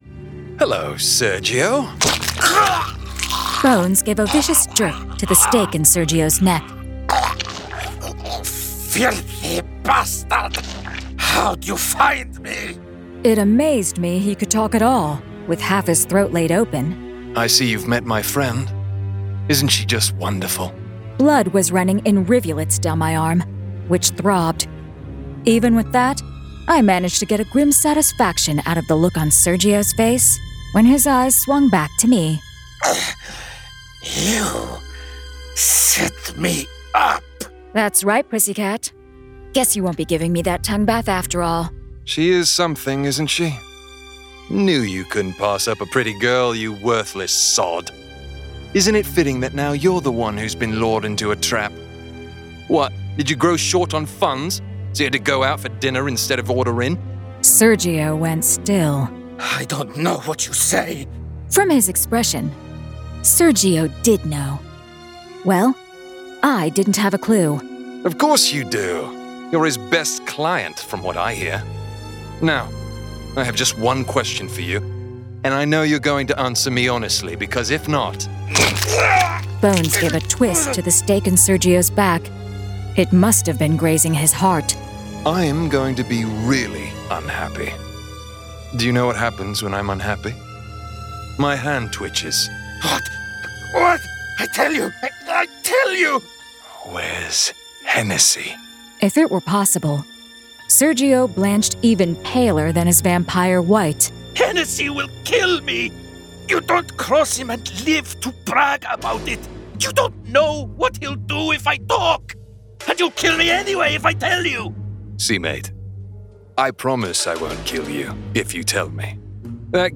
Full Cast. Cinematic Music. Sound Effects.
[Dramatized Adaptation]
Genre: Fantasy Romance